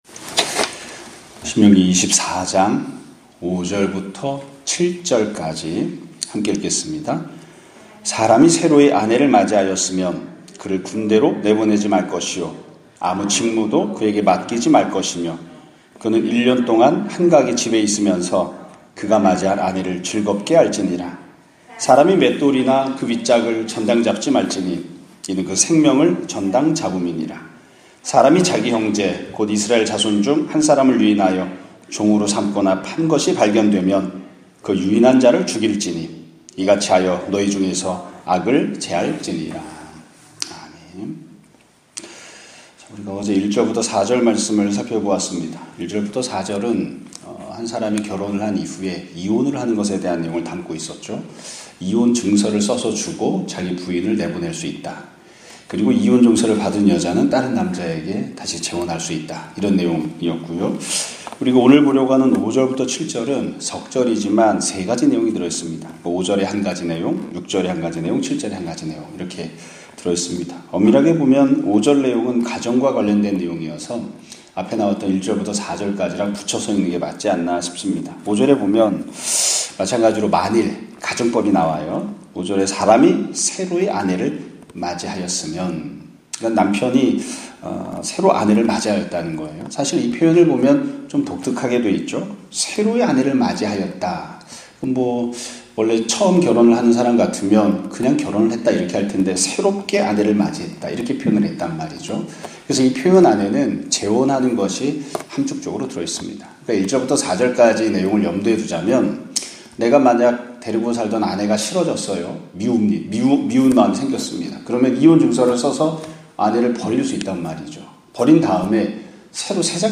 2024년 5월 8일(수 요일) <아침예배> 설교입니다.